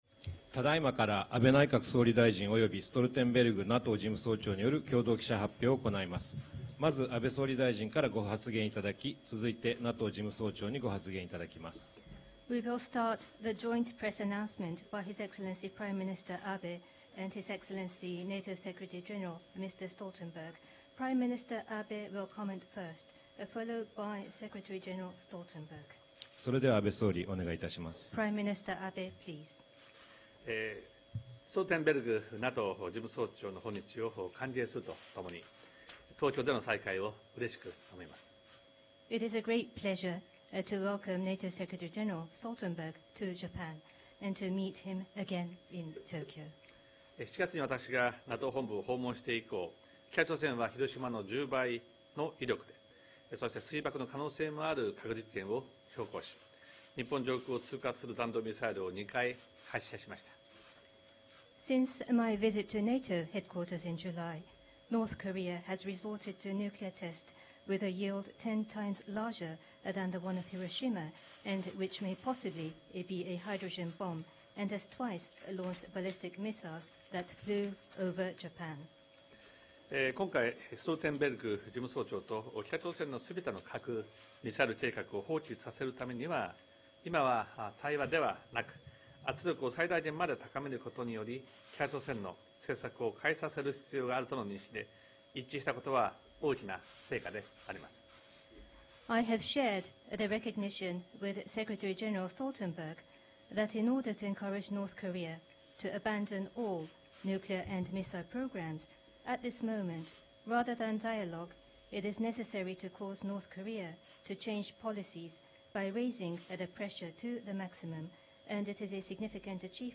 Joint press point
by NATO Secretary General Jens Stoltenberg and the Prime Minister of Japan, Shinzo Abe